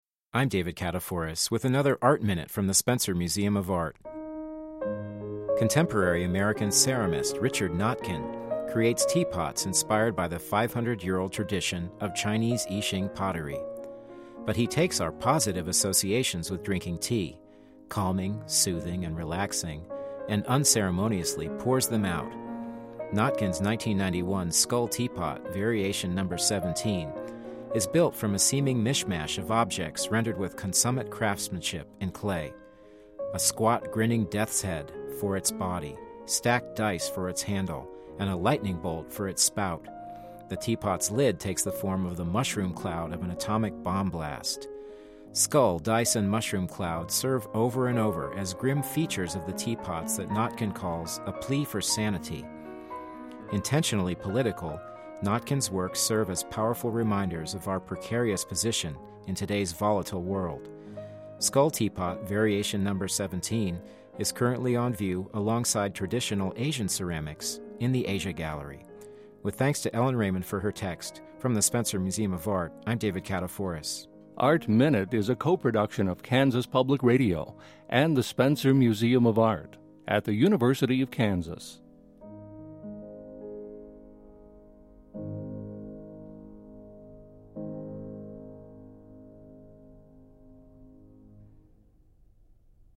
Didactic – Art Minute